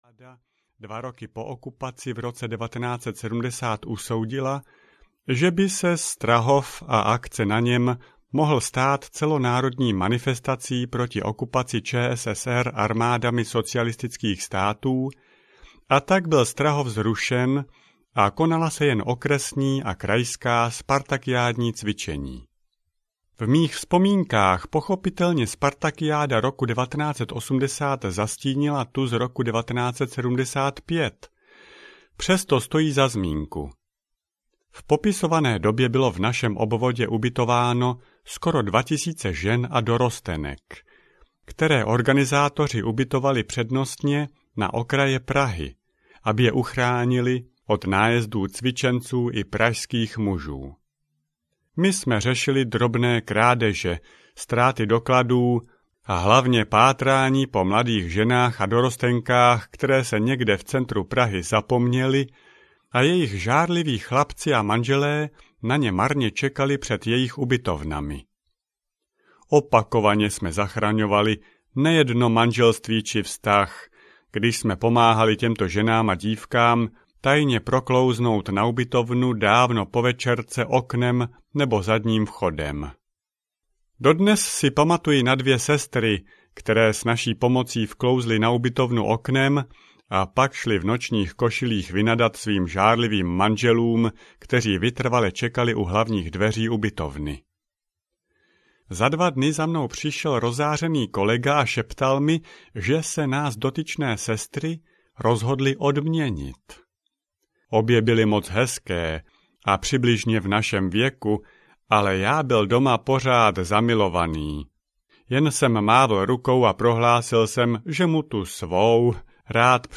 Krimi DEKAMERON 3 audiokniha
Ukázka z knihy